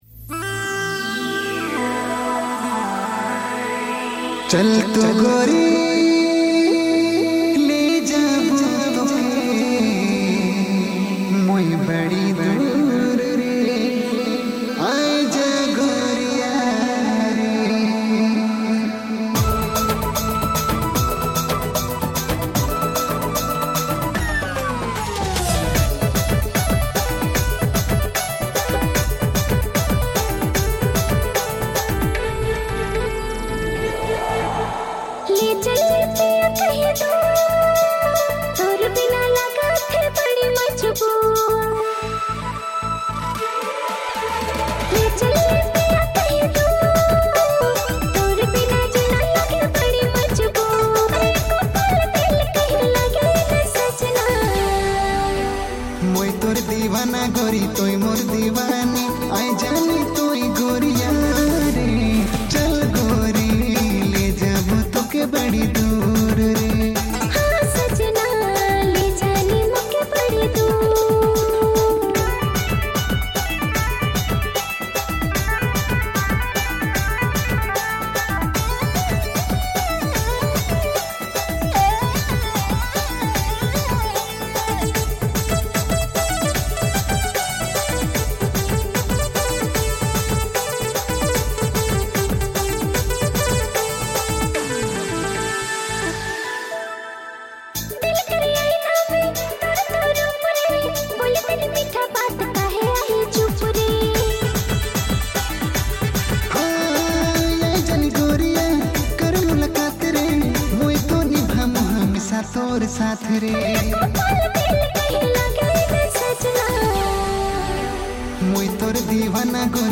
New Nagpuri No Voice Dj Song